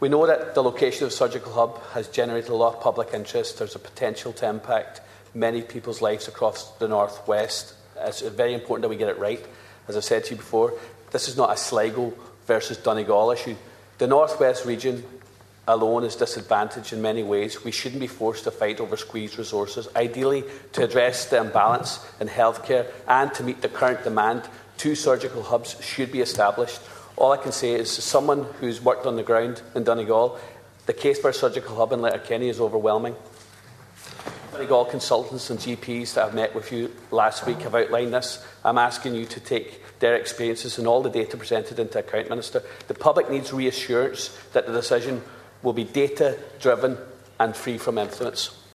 In the Dail, Donegal Deputy Charles Ward appealed to Minister Carroll MacNeill to ensure a decision on the surgical hub is based on data and not influence: